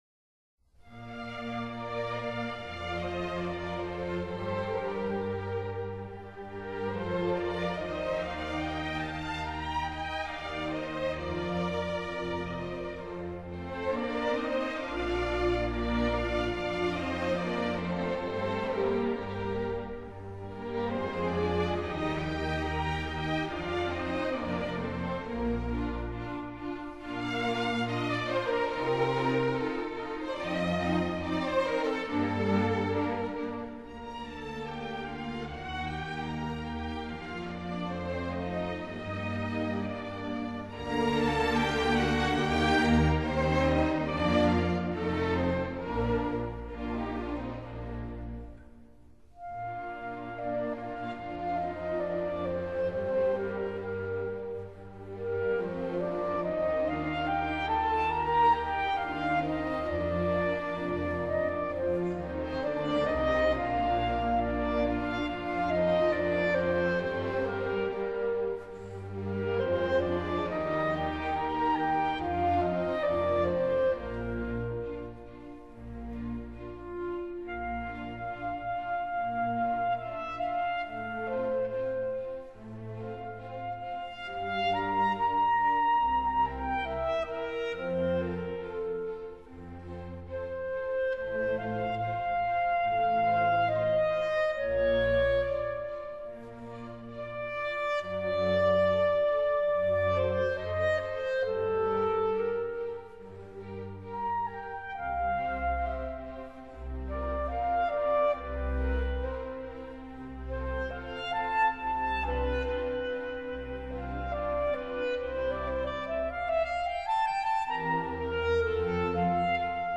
Clarinet Concerto in E flat major